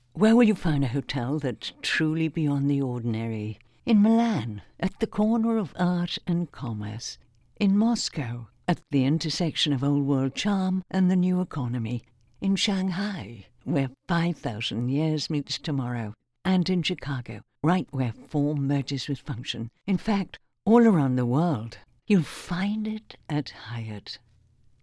Female
English (British)
Am a professional voice actor with a unique sound - relaxed yet formal, playful yet serious.
Studio Quality Sample
Home Studio Recording Hyatt
0324Home_recording_Hyatt.mp3